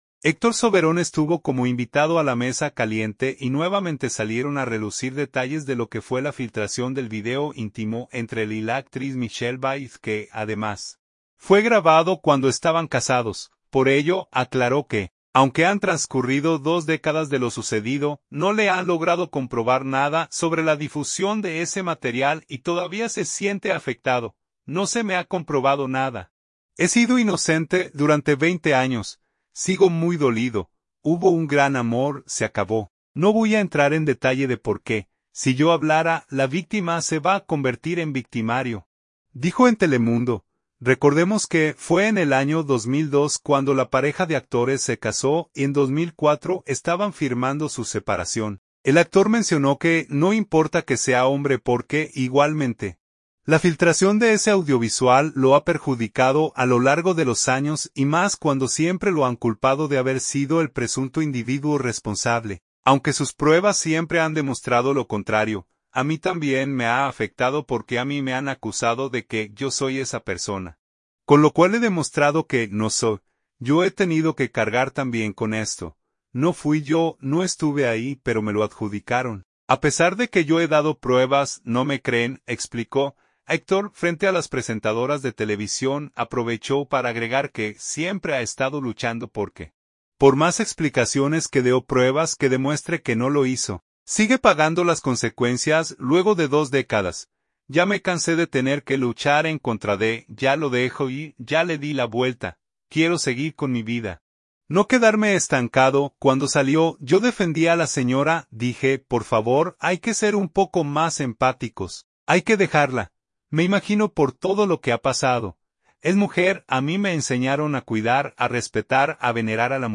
Héctor Soberón estuvo como invitado a “La Mesa Caliente” y nuevamente salieron a relucir detalles de lo que fue la filtración del video íntimo entre él y la actriz Michelle Vieth que, además, fue grabado cuando estaban casados.